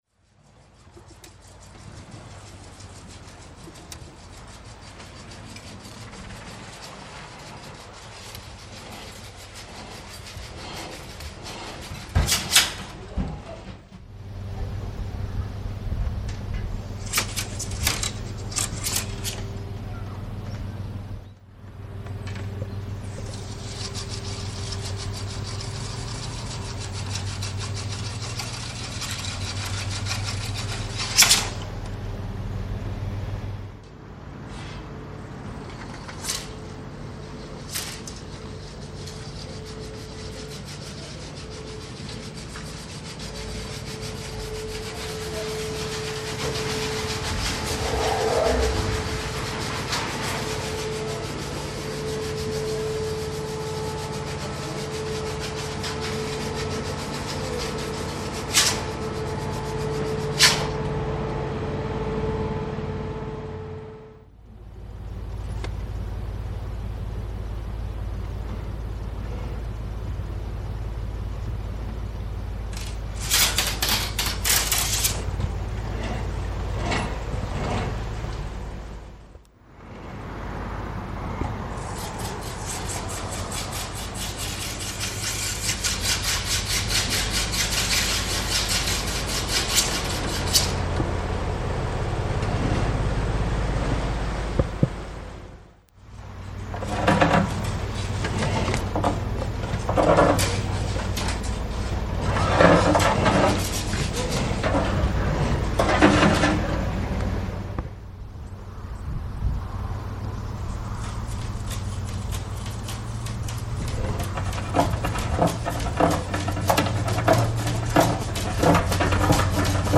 Field Recording Series by Gruenrekorder
Die Künstler fahren unter die Brücken und kratzen mit den Ruten an ihnen entlang, nehmen die Geräusche auf.